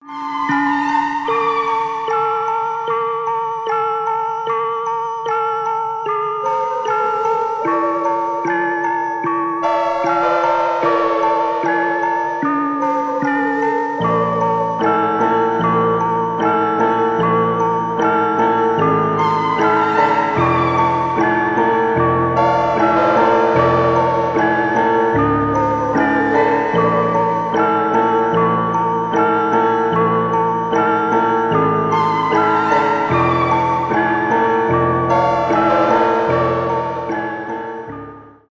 без слов , электронные , ambient
атмосферные , грустные